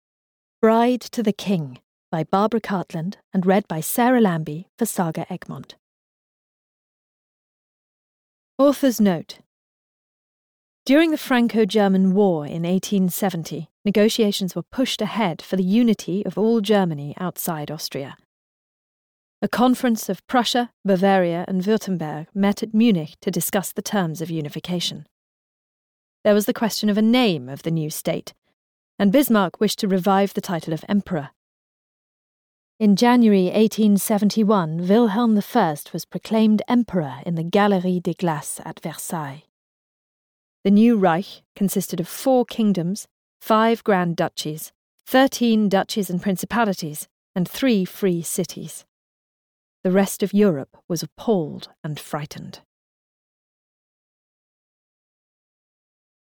Bride to the King (EN) audiokniha
Ukázka z knihy